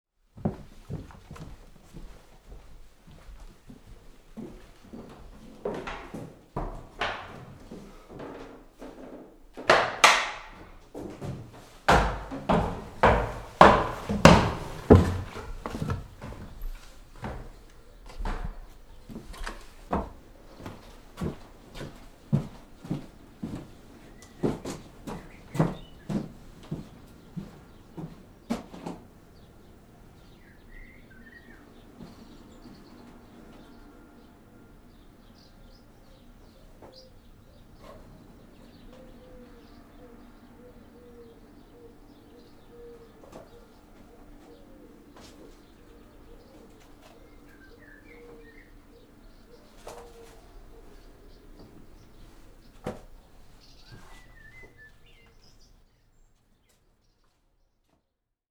Toutefois, voici quelques exemples anonymes de sons qui ont accompagné un témoignage.
Revasserie-2-grenier.wav